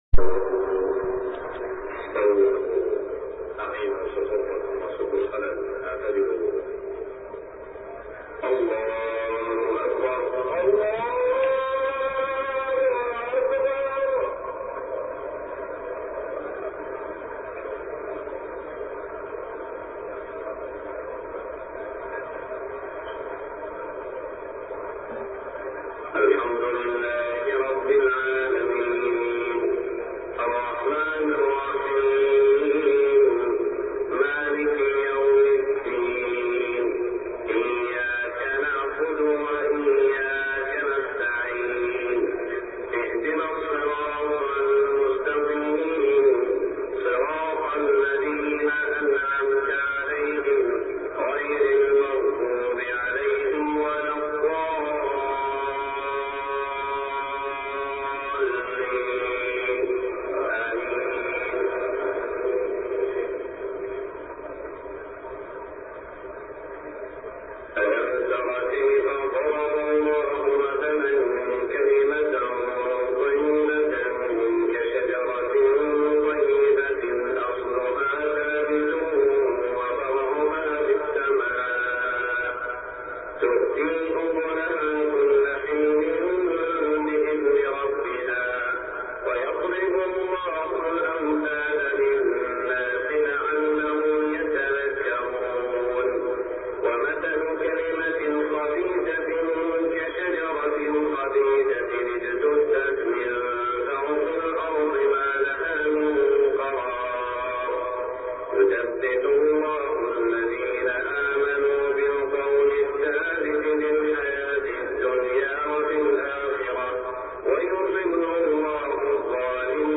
صلاة الفجر يوم عيد الأضحى 1422هـ من سورة إبراهيم 24-34 > 1422 🕋 > الفروض - تلاوات الحرمين